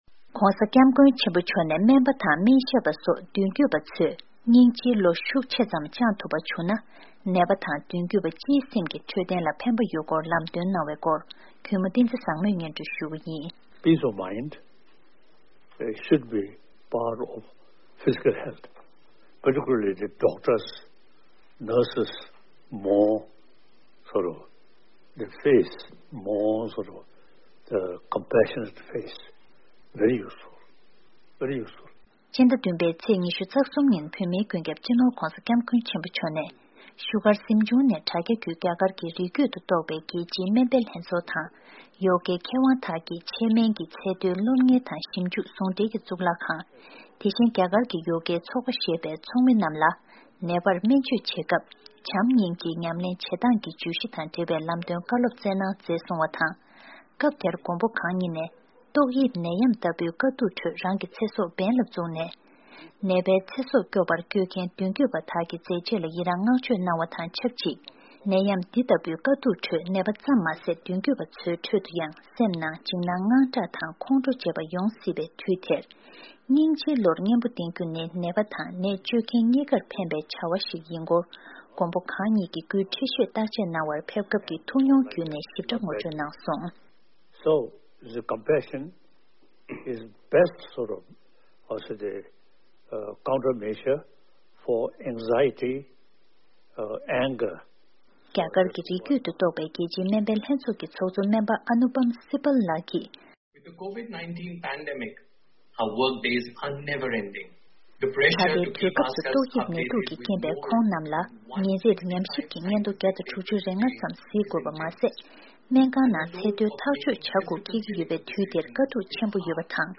༧གོང་ས་མཆོག་གིས་རྒྱ་གར་བའི་སྨན་པའི་ལྷན་ཚོགས་ལ་གསུང་བཤད།